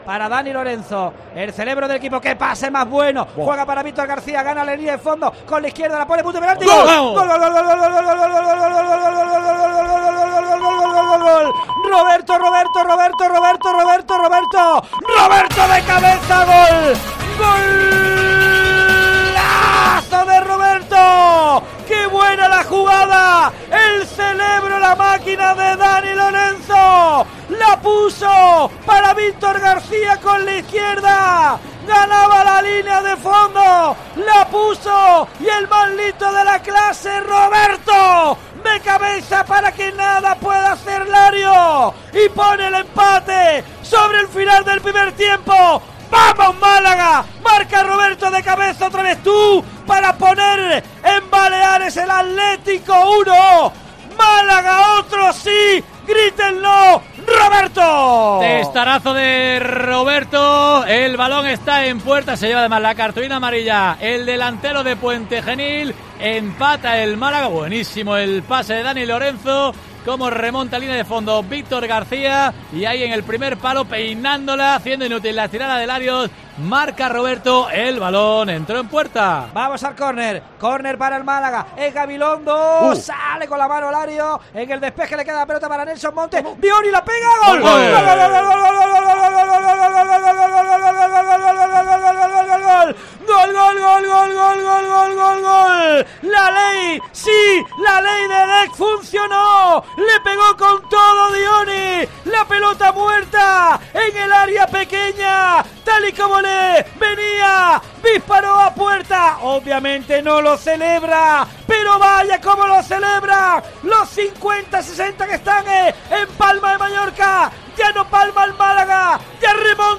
Así sonó en COPE Málaga la remontada del Málaga con goles de Roberto y Dioni